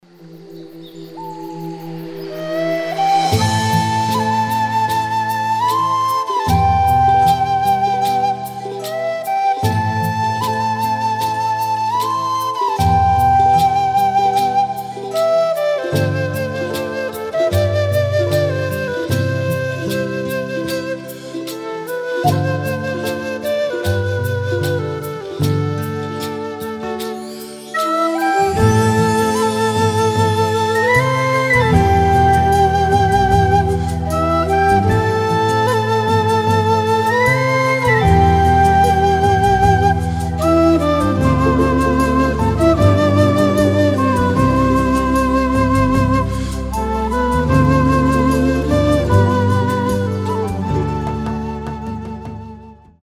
спокойные
без слов
красивая мелодия
расслабляющие
Флейта
Пение птиц
New Age